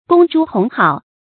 公諸同好 注音： ㄍㄨㄙ ㄓㄨ ㄊㄨㄙˊ ㄏㄠˋ 讀音讀法： 意思解釋： 公：公開；諸：「之于」的合音；同好：愛好相同的人。